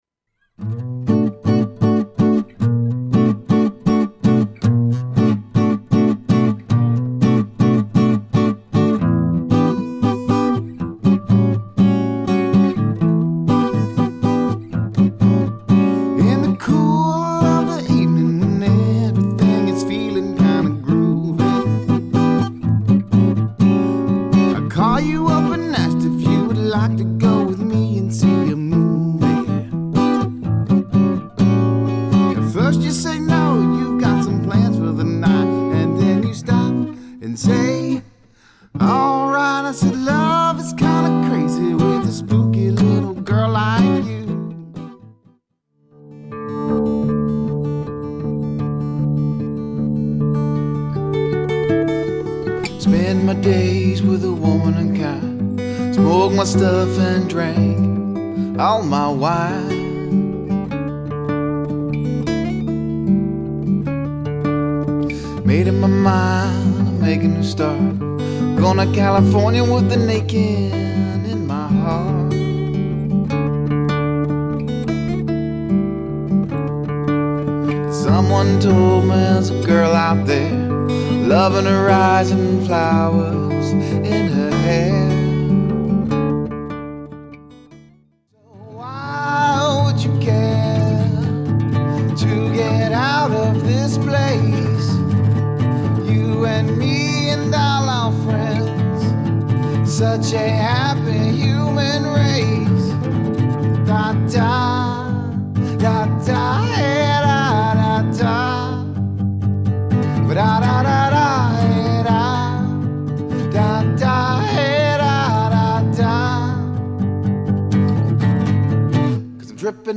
acoustic solo